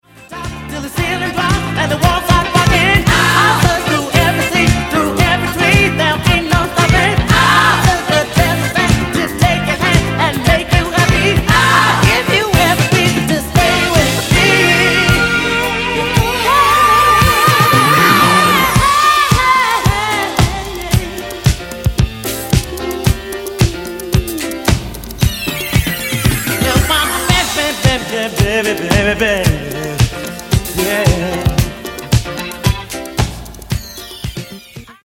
Genere:   Disco | Funky | Soul